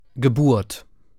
Aussprache:
🔉[ɡəˈbuːɐ̯t]